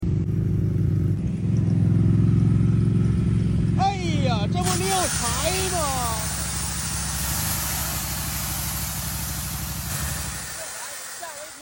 Motorcycle sound effects free download